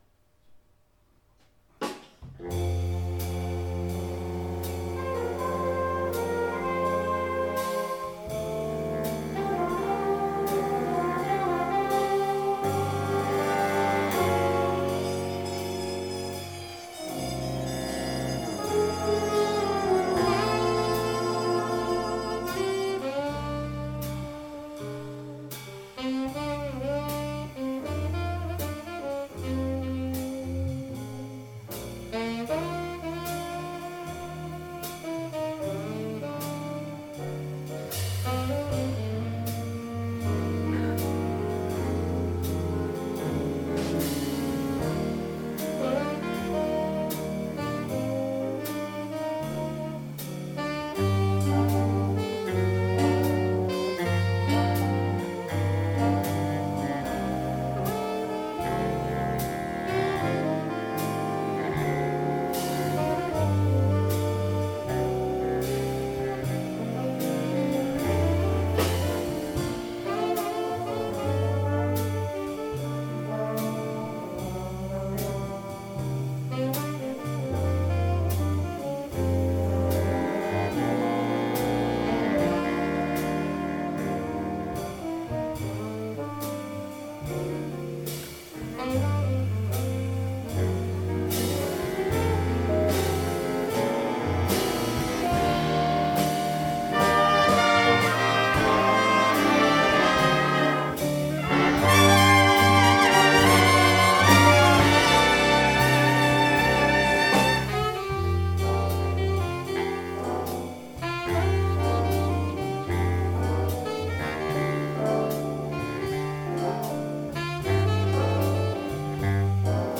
- Hayburner Big Band 26. oktober 2014
Introduktion af nummeret